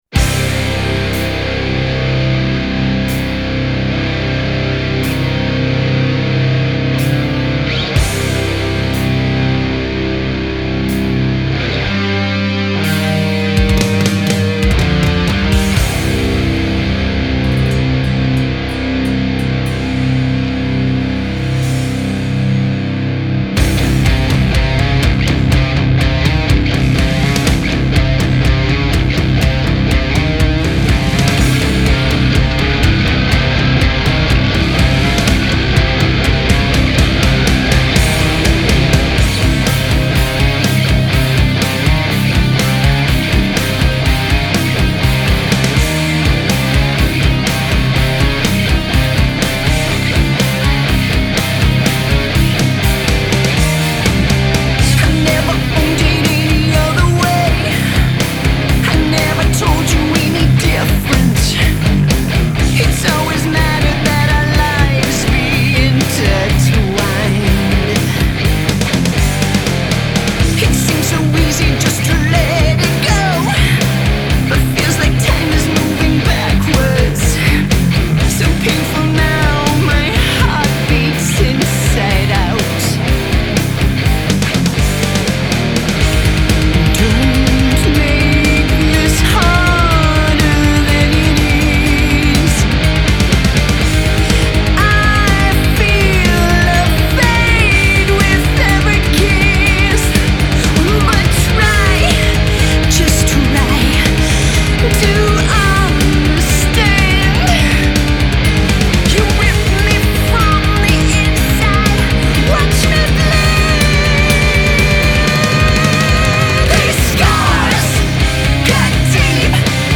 Genre: Metal.